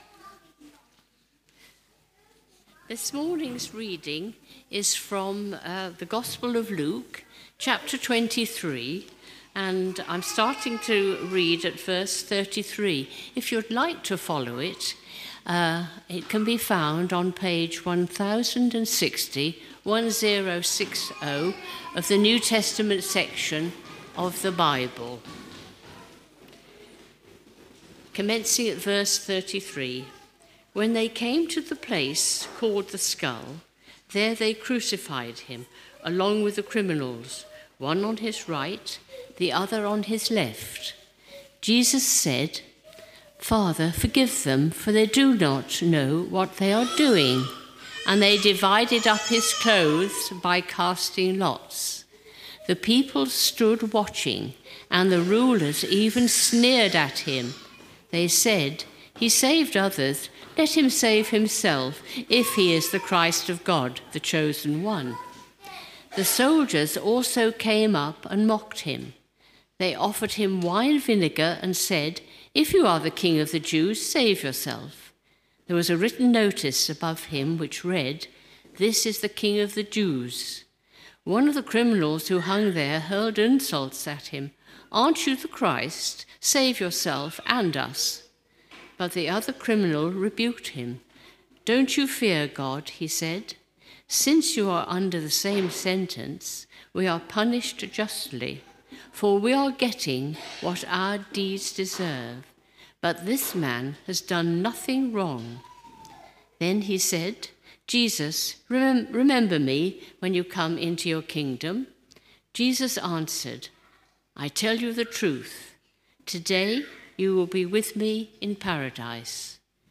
Due to the highly interactive nature of this morning’s sermon, this morning’s sermon is only available as an audio recording.